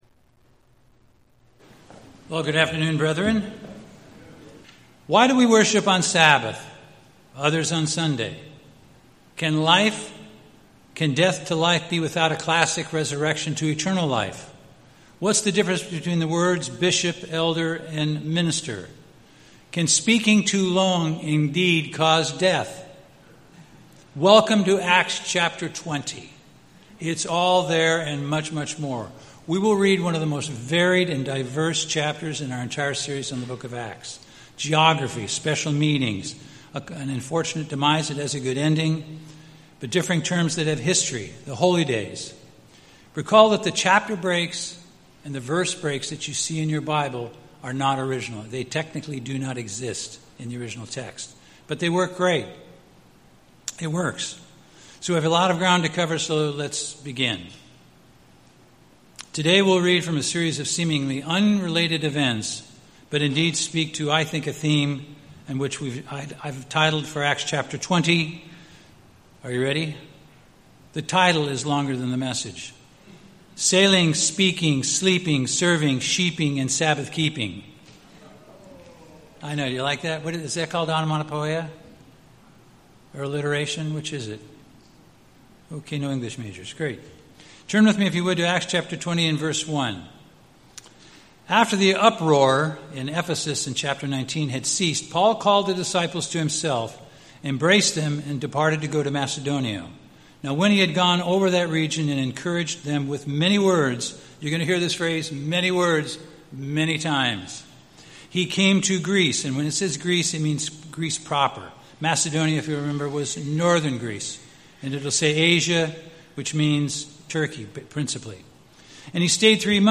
Sermons
Given in Bakersfield, CA Los Angeles, CA